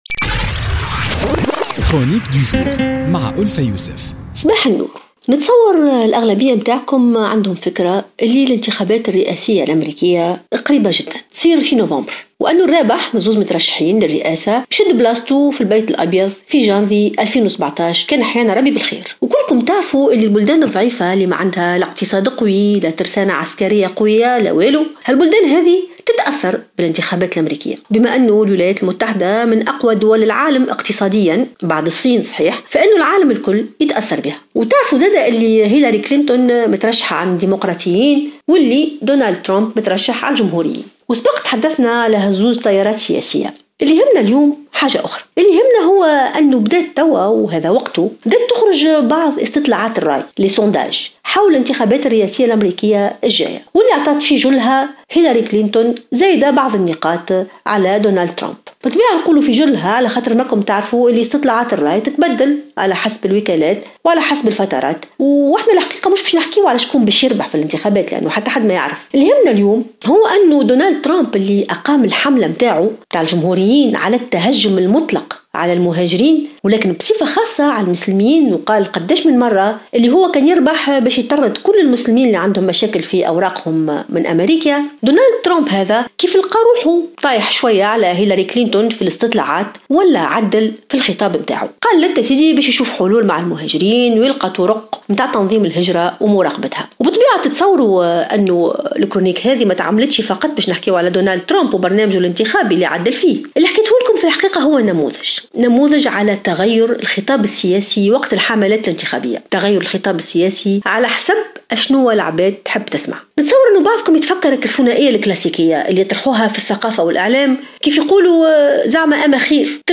انتقدت الكاتبة ألفة يوسف في افتتاحيتها بـ "الجوهرة اف أم" اليوم الثلاثاء، وعود السياسيين الكاذبة وتلوّنهم في كل مناسبة انتخابية على غرار ما هو حاصل حاليا في الولايات المتحدة.